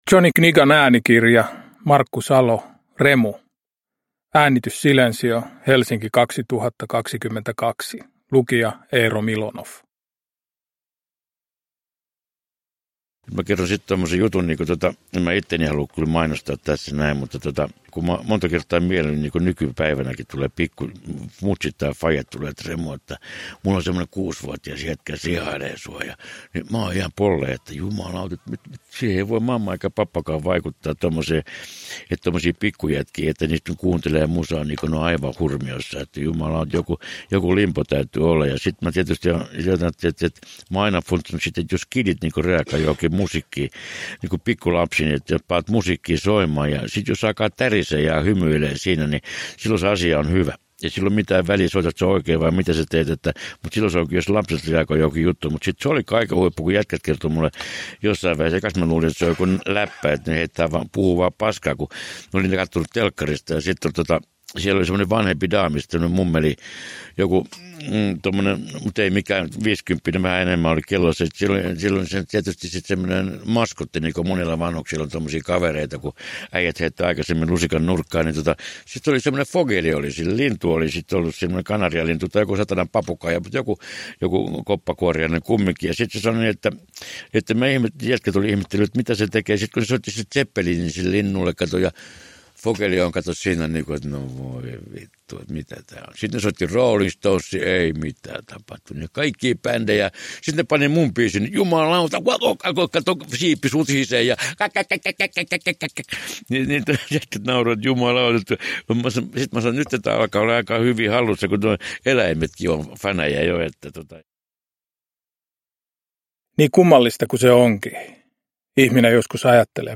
Remu – Ljudbok – Laddas ner
Päivitetty, omaääninen elämäkerta Remu Aaltosesta.
Kirja kertoo Remun elämän ylä- ja alamäet remunkielisesti ja riemumielisesti, isännän äänellä.
Uppläsare: Remu Aaltonen, Eero Milonoff